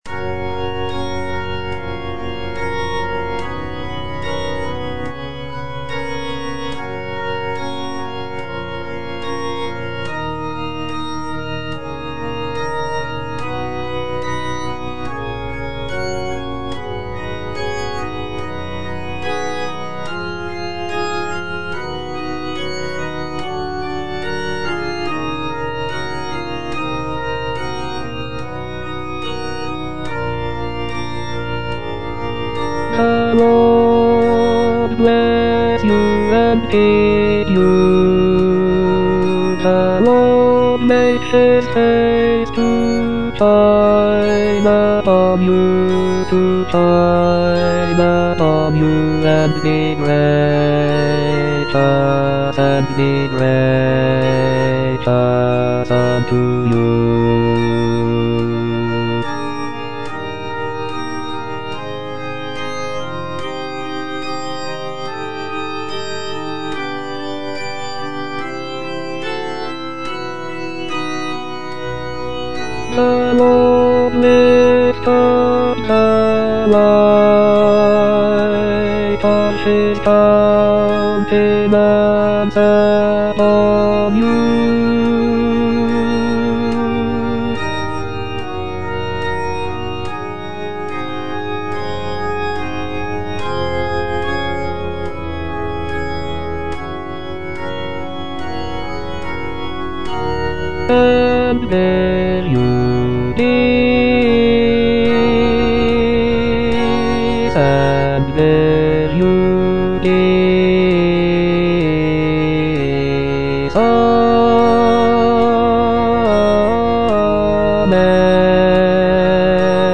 Bass (Voice with metronome)
choral benediction